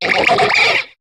Cri de Croâporal dans Pokémon HOME.